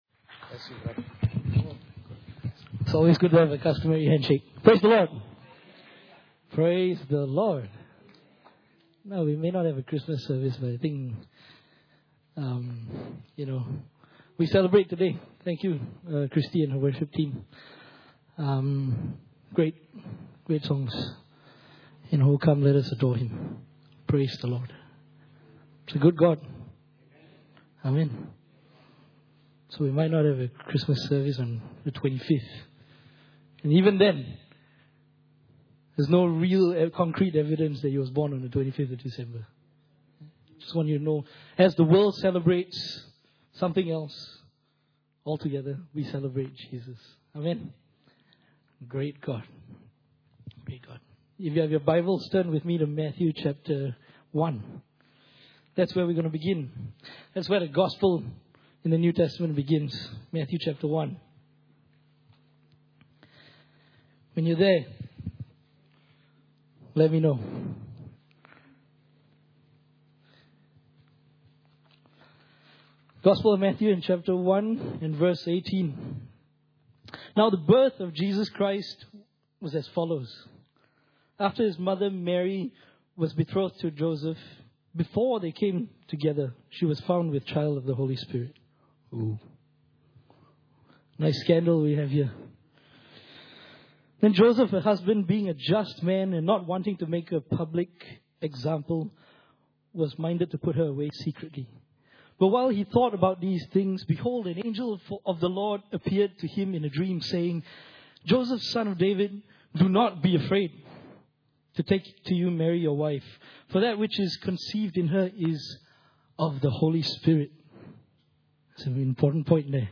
Emmanuel Christmas message